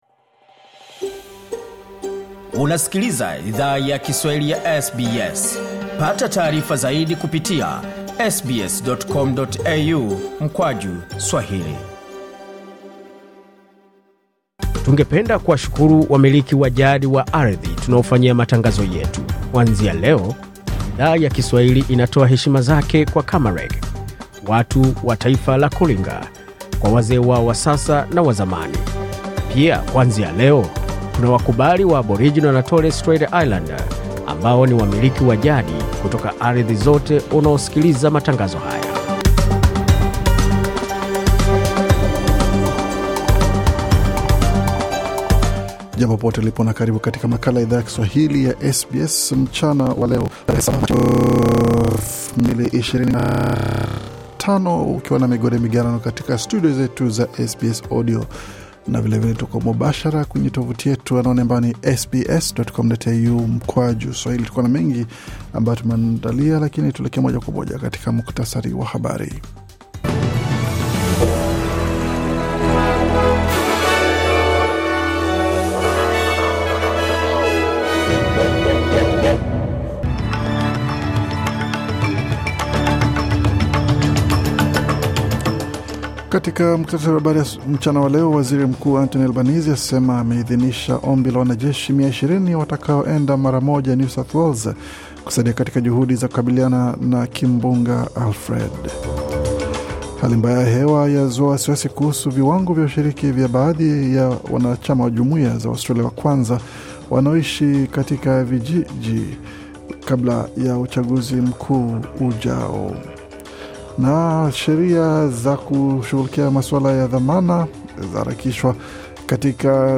Taarifa ya Habari 7 Machi 2025